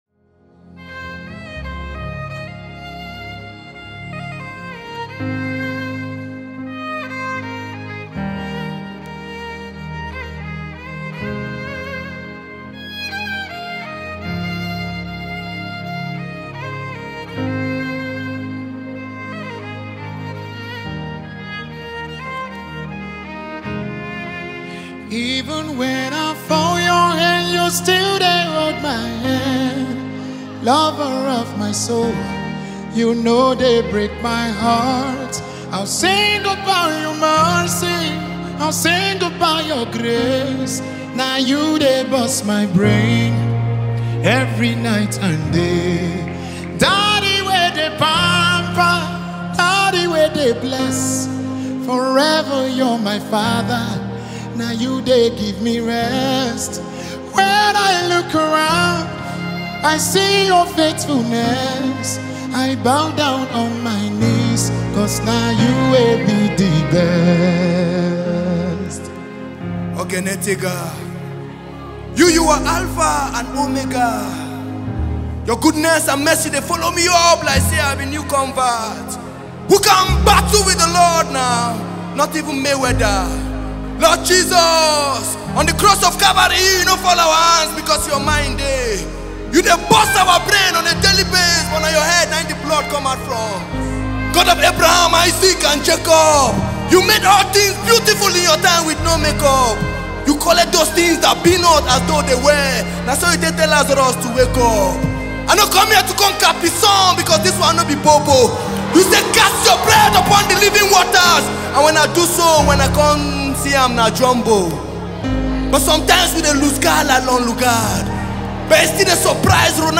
a heartfelt song
This live ministration
delivers uplifting praise and worship
Genre: Gospel